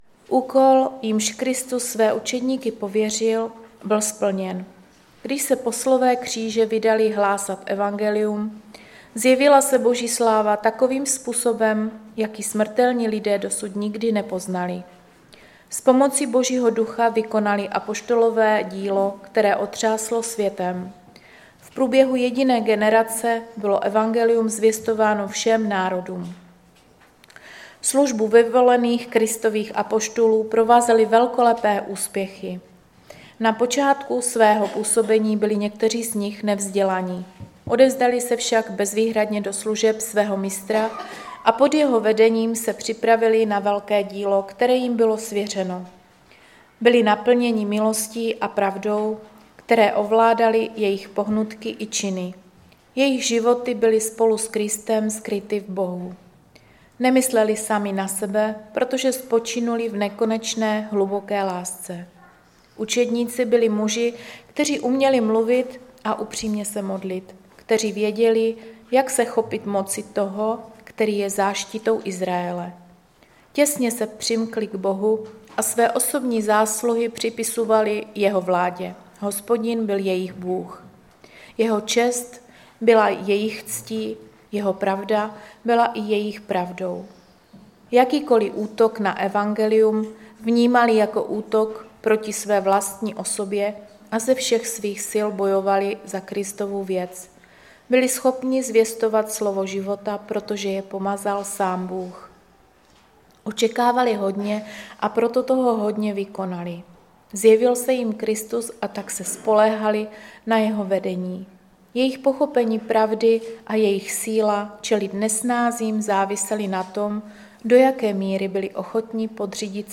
Sbor Ostrava-Radvanice. Shrnutí přednášky začíná kolem 13-té minuty.